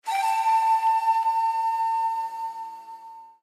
Sound Effects
The Best Doorbell